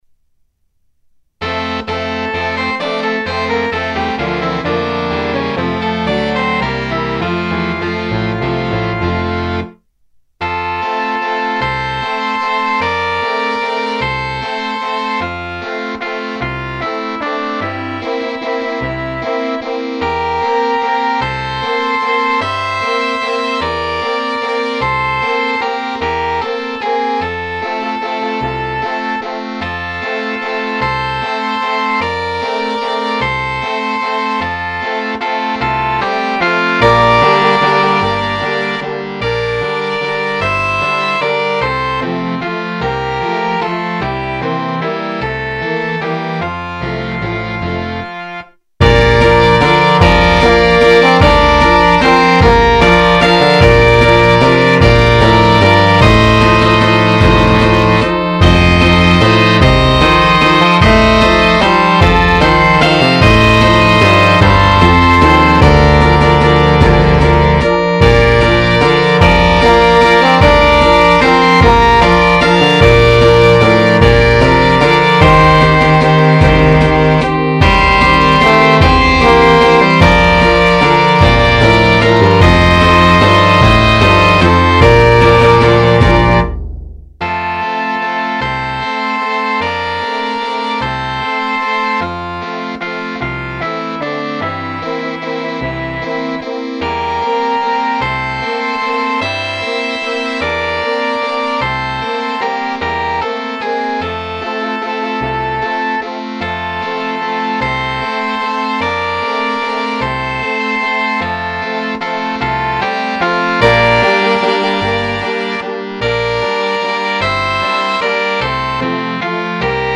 Pour orchestre :
leletteOrchestre.mp3